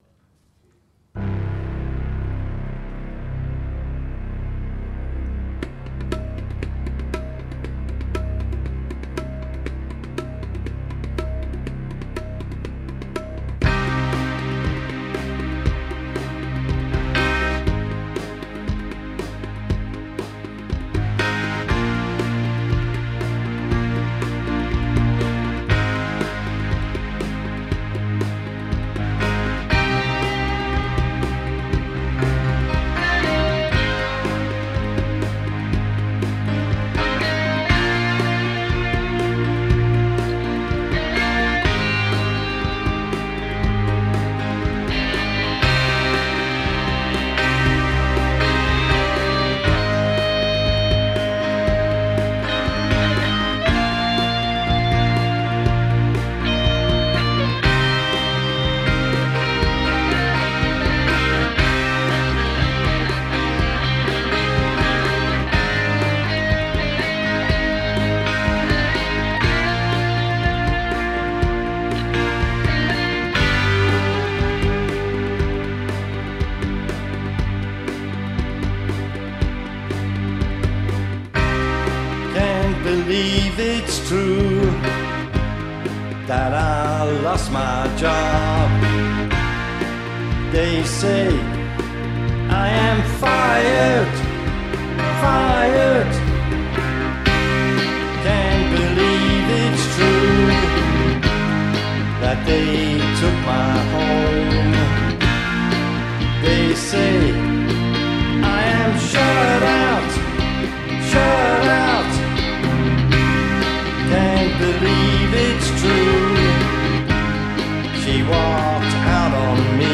Tabla und Drums